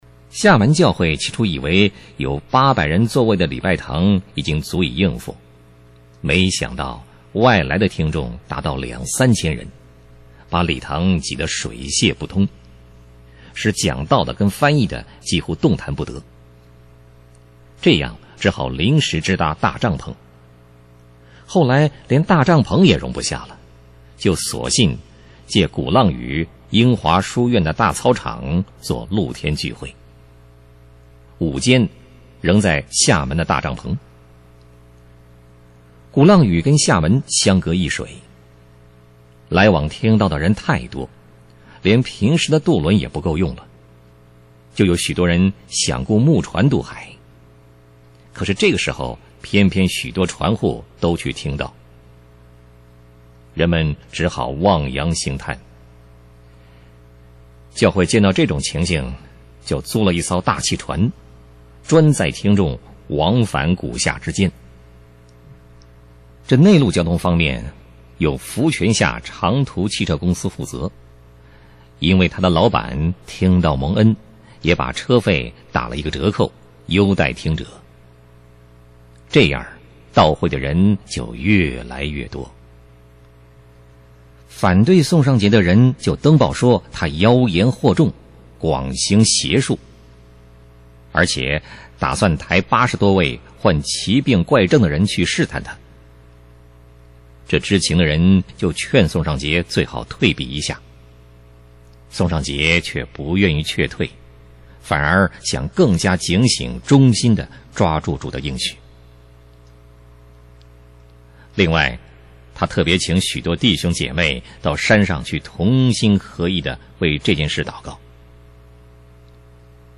（音频、文字均选自网络，如若侵权请联系我们。较之文字，音频内容有浓缩，详细请阅读全文↓）